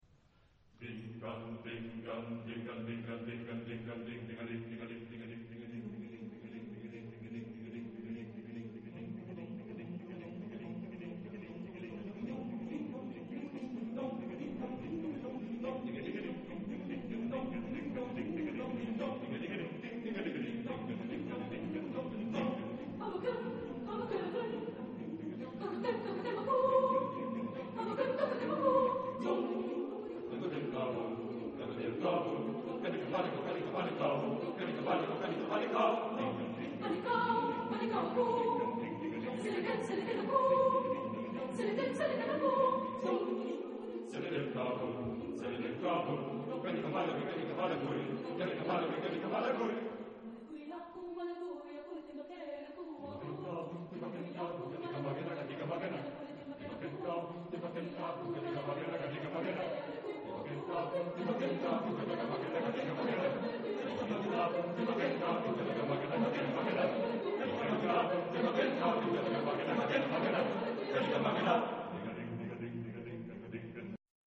Género/Estilo/Forma: Profano
Carácter de la pieza : libremente
Tipo de formación coral: SATB  (4 voces Coro mixto )
Tonalidad : si bemol mayor